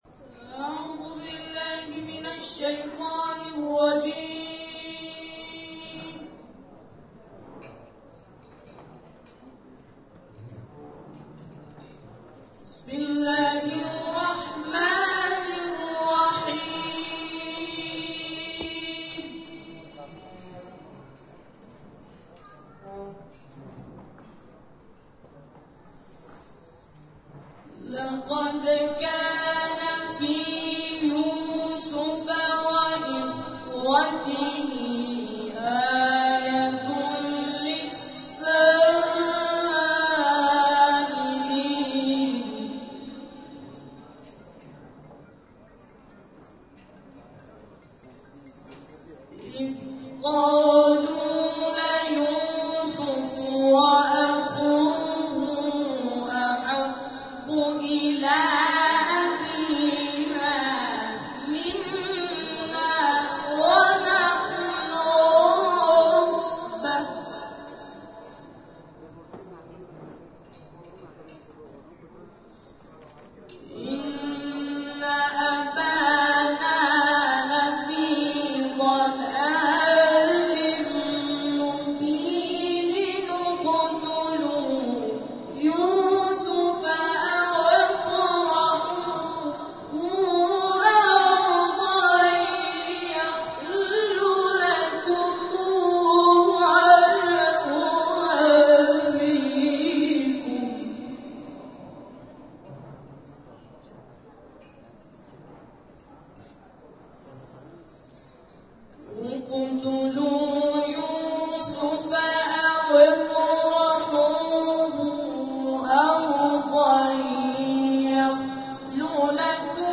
در ابتدای این مراسم گروه همخوانی سبیل الرشاد به اجرای تلاوت آیاتی از کلام الله مجید به سبک استاد مصطفی اسماعیل پرداختند.
در ادامه همخوانی گروه سبیل الرشاد ارائه می‌شود.